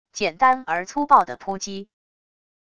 简单而粗暴的扑击wav音频